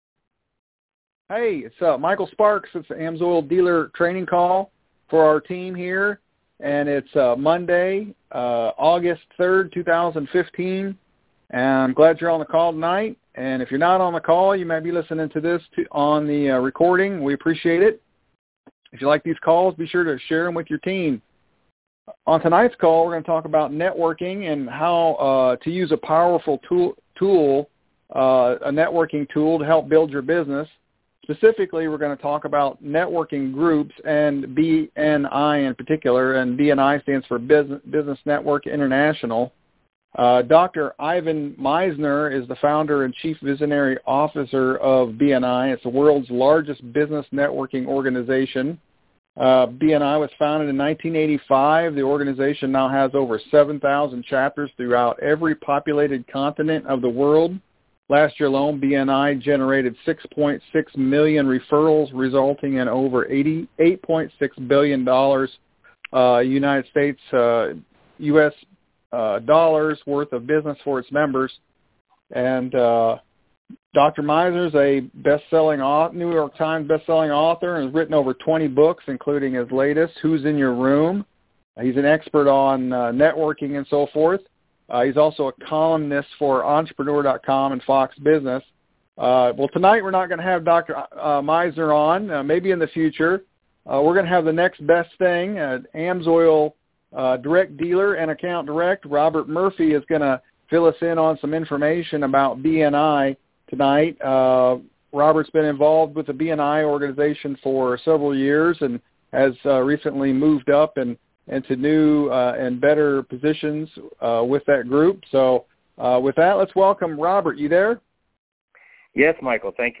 Sparks Team AMSOIL Dealer Training Call |August 3rd, 2015